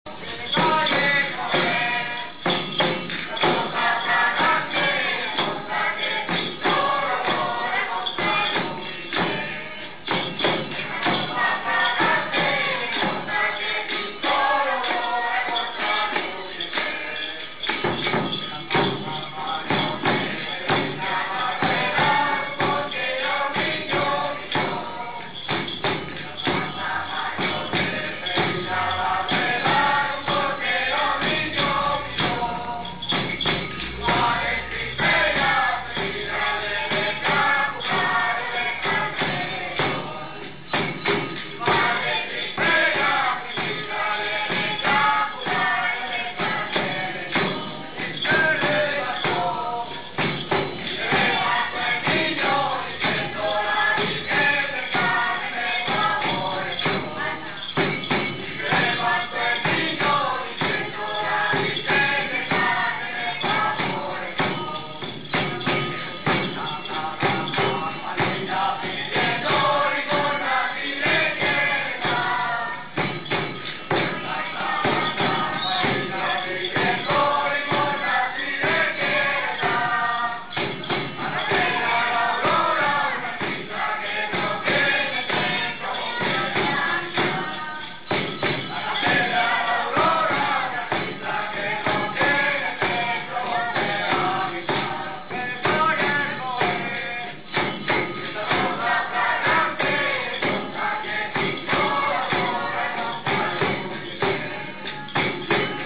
El "tan tán tan tararán tan" de la tambora, acompasado, sonoro y enérgico les llega al alma a los cambileños.
Hay muchas estrofas, el poeta no escatimó, pero la gente repite sólo unas pocas y éstas cantadas con desorden y un poco atropelladamente.
También algunos echan sus traguillos, los que van toda la noche acompañando con sus instrumentos de cuerda.